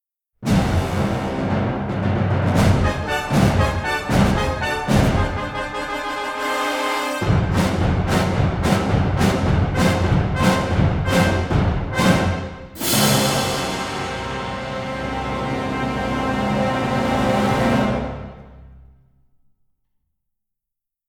Both scores were recorded in January 2023